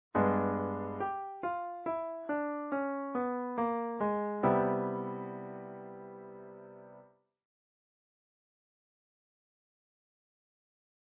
As part of our V7 family of chords, the flat Nine color finds its way into some interesting combinations with its color tone brethren, i.e., b5, #11, 13 etc. Hear the flat Nine sounds in action first, then we'll explore its theory. Thinking V7b9 to One in the key of C major.
A one of a kind sound ?